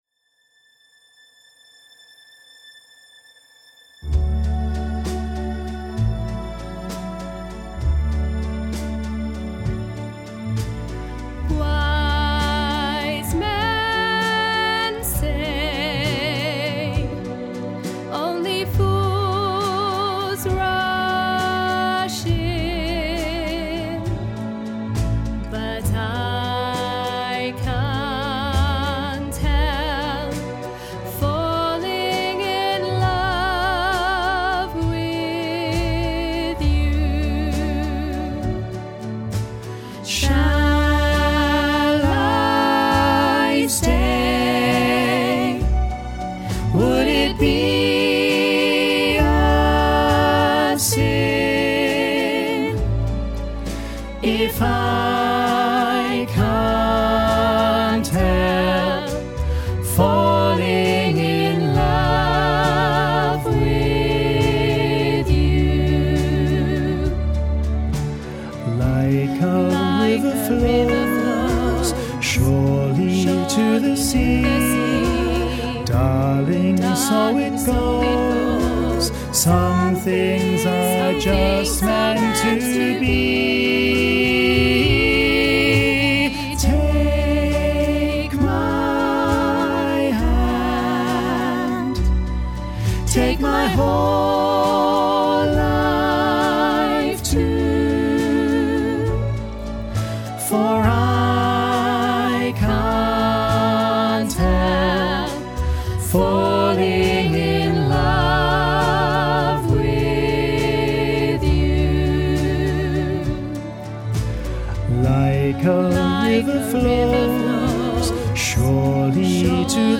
Listen to bass track with soprano and alto accompaniment